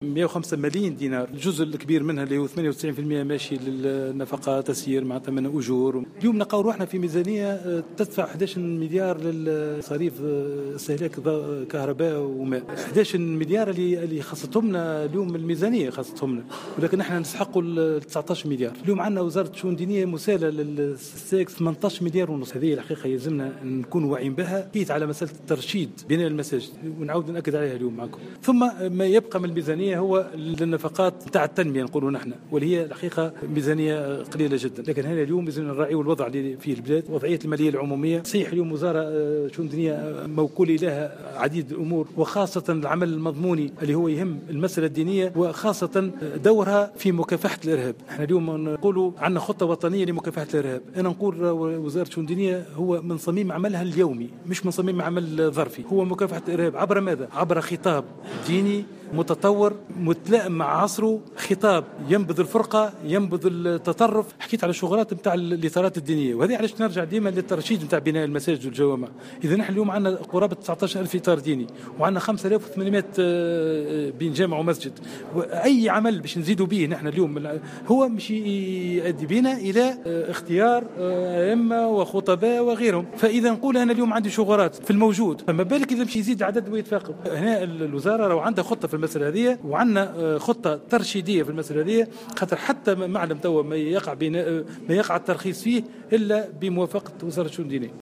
وقدّرت ميزانية وزارة الشؤون الدينية لسنة 2018، وفق تصريح الوزير لمراسل الجوهرة اف ام اثر جلسة استماع له اليوم بلجنة الحقوق والحريات بالبرلمان في إطار مناقشة مشروع قانون المالية 2018، قدّرت ب105.305 مليون دينار ستخصص 98 بالمائة منها لنفقات التصرف.